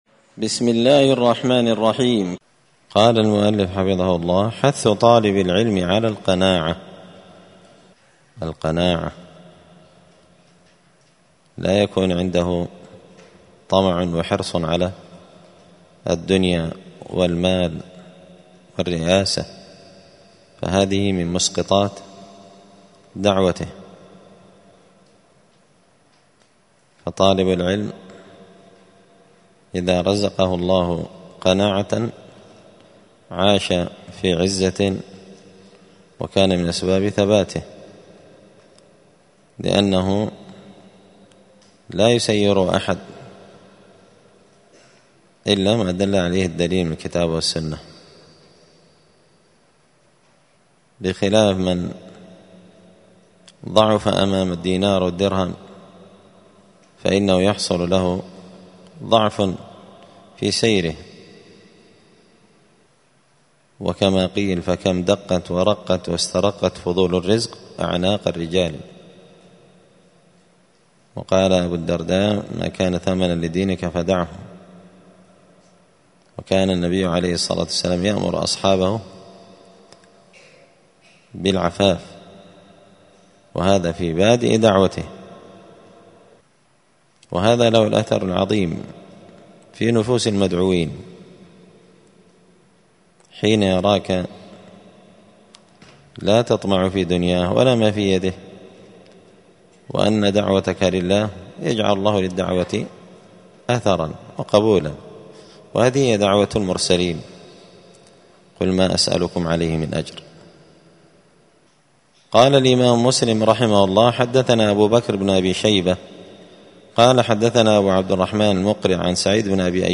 دار الحديث السلفية بمسجد الفرقان بقشن المهرة اليمن
*الدرس الثالث والعشرون (23) باب حث طالب العلم على القناعة*